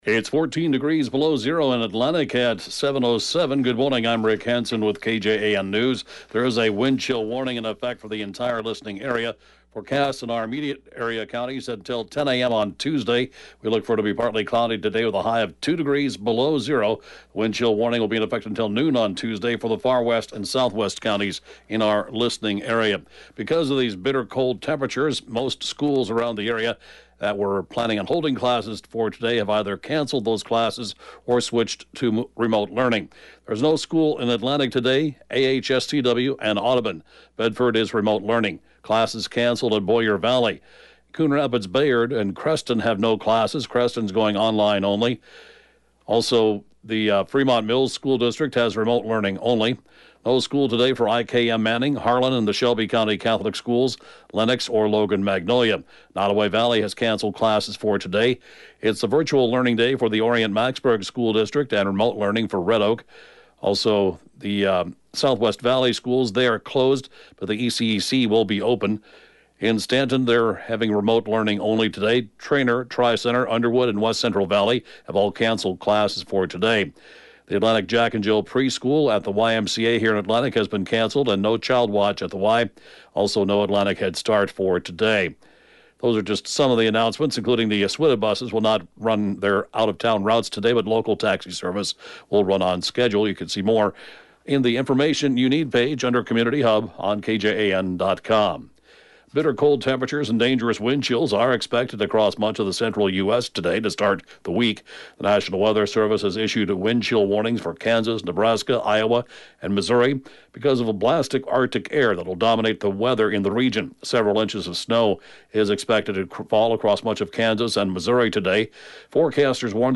The 7:07-a.m. Newscast